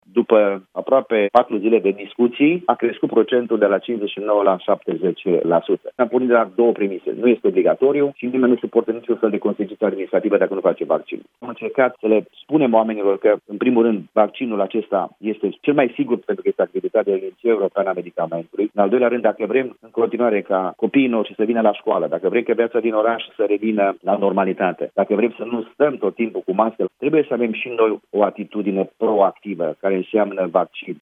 În Cluj, 70 la sută dintre angajații primăriei doresc să se vaccineze, a declarat la Europa FM, primarul Emil Boc. El spune că numărul au fost de acord cu vaccinarea a crescut după doar 4 zile de informări despre avantajele și riscurile pe care le prespune această injecție.